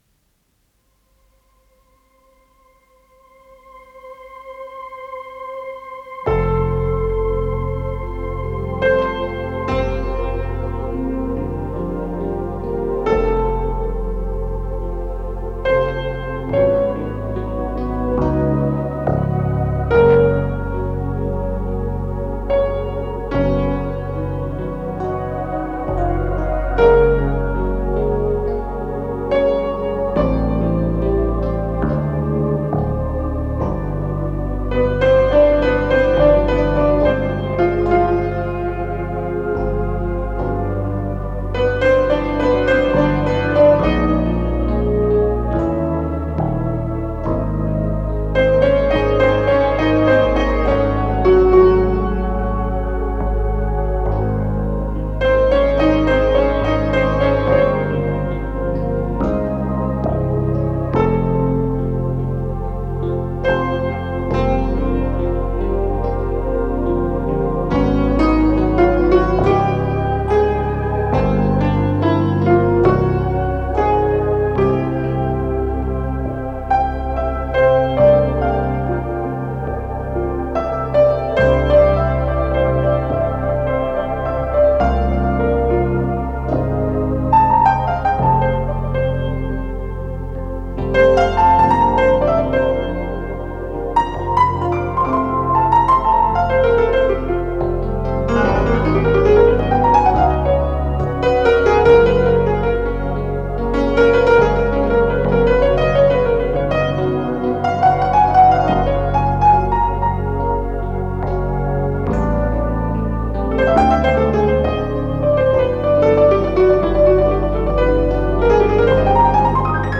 РедакцияМузыкальная
электронные клавишные инструменты, фортепиано
ВариантДубль моно